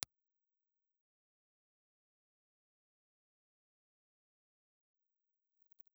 Impulse response recorded with the front side of a C&T Naked Eye ribbon microphone
C&T_NakedEye_Front_IR.wav
In my opinion the sound from the Roswellite is slightly darker than aluminium foil and the noise floor is a little higher.